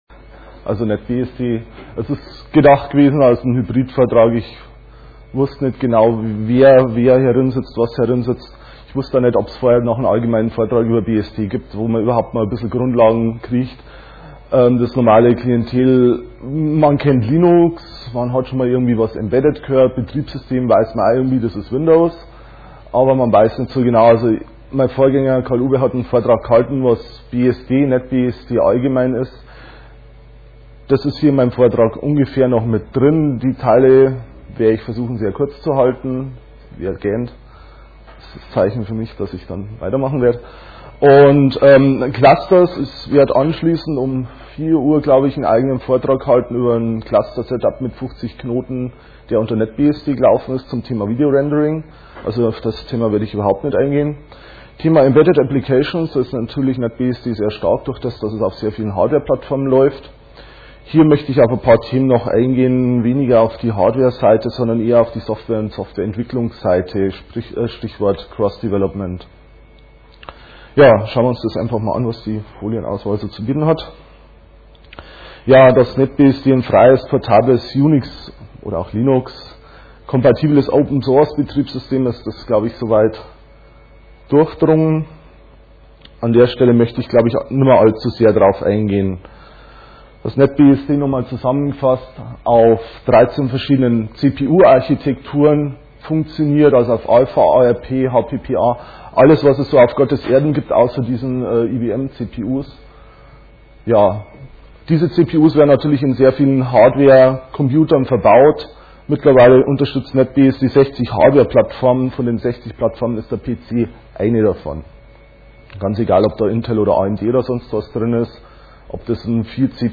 5. Chemnitzer Linux-Tag
Sonntag, 15:00 Uhr im Raum V1 - NetBSD
Vortragsmittschnitt